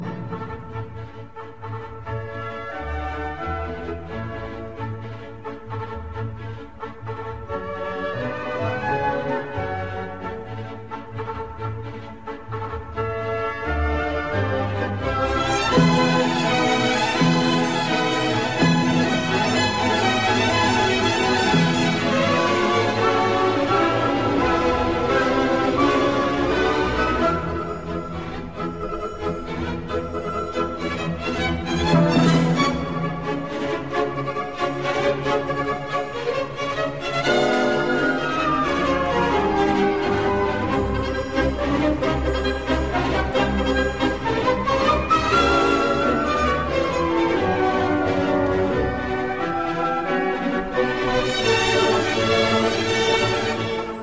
Triumfální symfonie (MP3) (III.Scherzo, hraje Česká filharmonie, dir. Alois Klíma, z vysílání ČR Plzeň 1.1.2008)